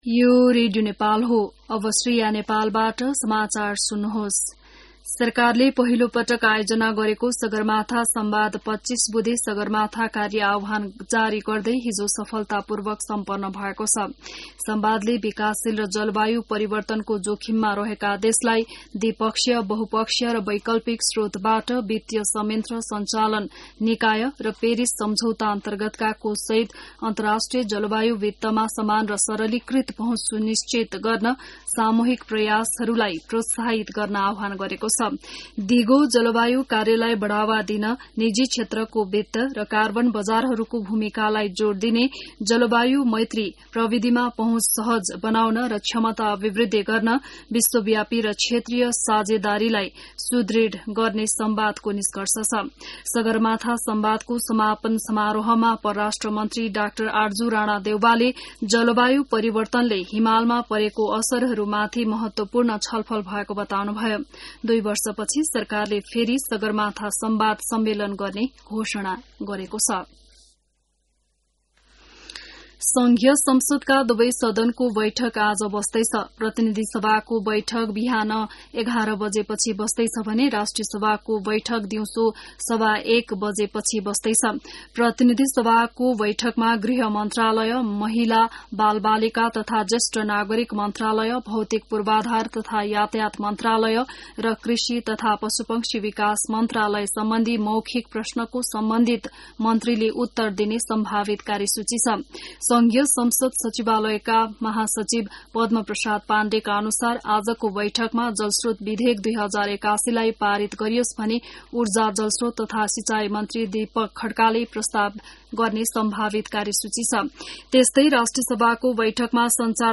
बिहान १० बजेको नेपाली समाचार : ५ जेठ , २०८२